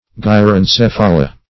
Meaning of gyrencephala. gyrencephala synonyms, pronunciation, spelling and more from Free Dictionary.
gyrencephala.mp3